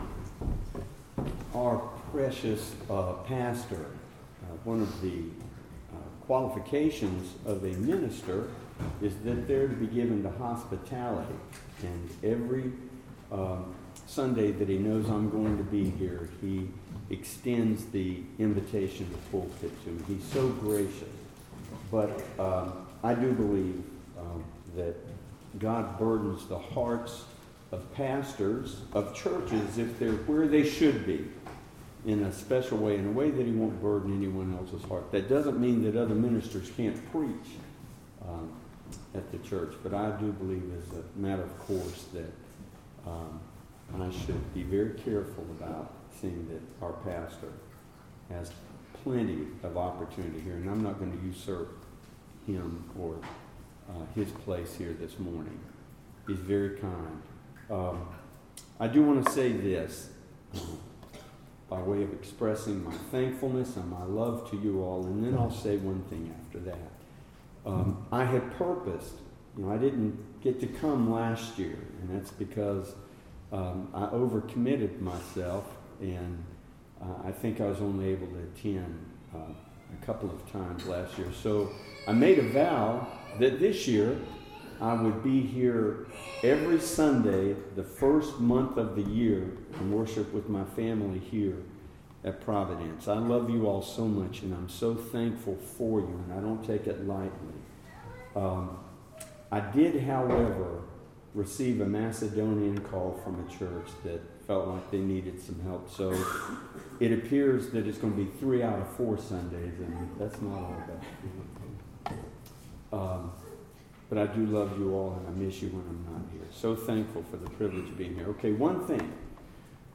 Topic: Sermons